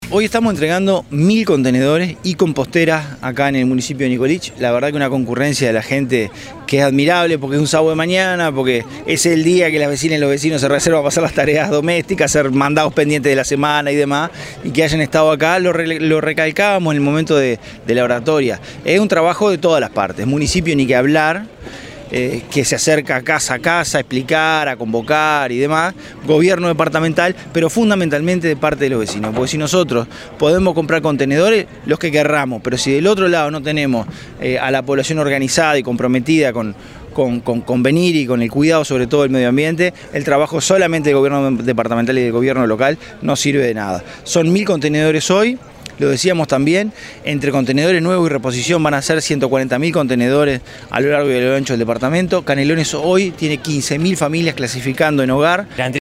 El Secretario General de la Intendencia de Canelones, Dr. Esc. Francisco Legnani, dijo que la concurrencia fue “admirable”, ya que se trata “de un trabajo de todos: del municipio, del Gobierno Departamental, pero en especial de los vecinos y las vecinas”.
secretario_general_francisco_legnani_1.mp3